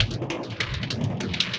Index of /musicradar/rhythmic-inspiration-samples/150bpm